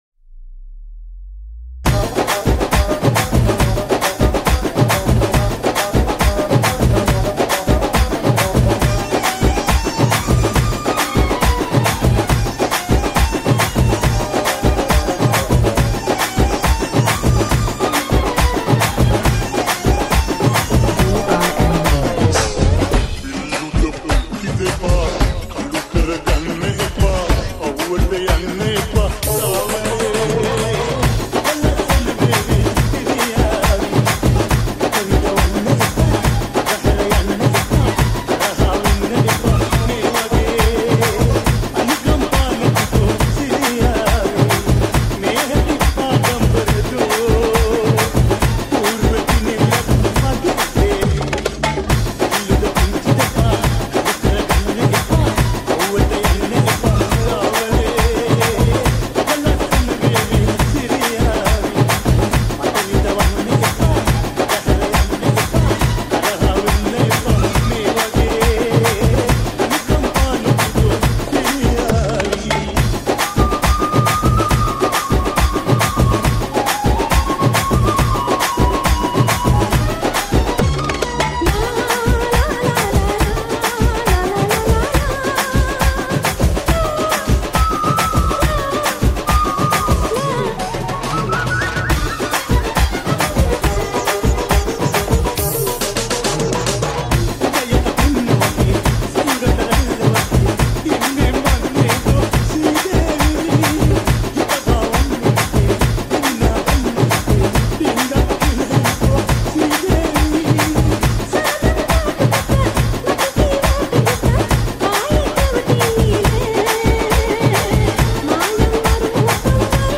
Baila Dance Mix